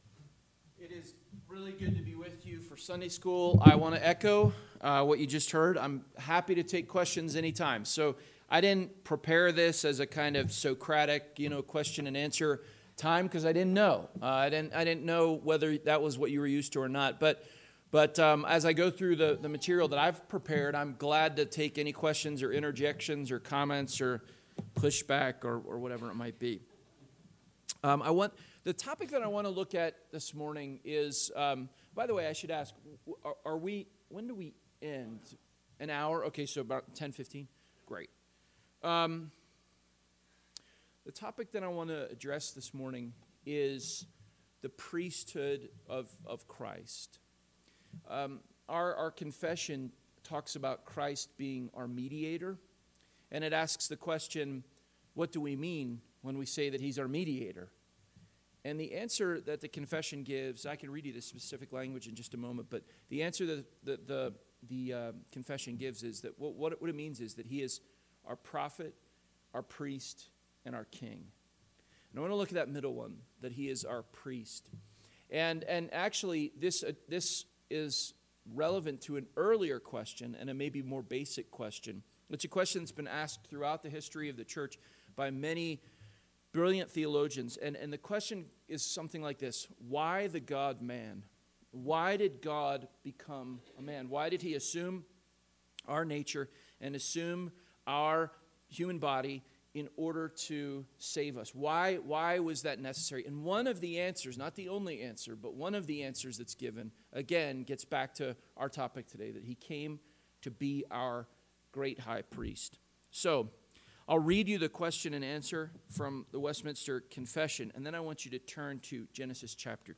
2022 Cheyenne Reformation Conference – Sunday School – Northwoods Sermons